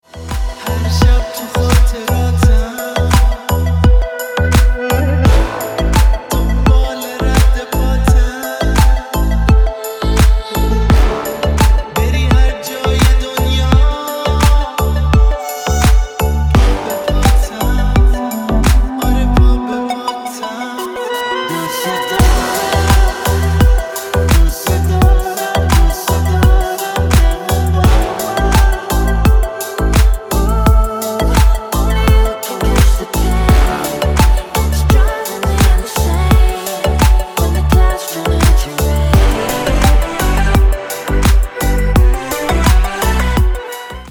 теги: красивый рингтон